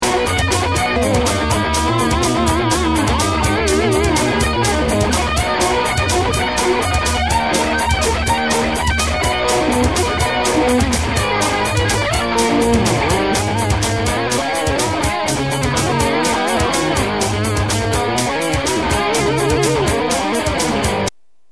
solo clip: MP3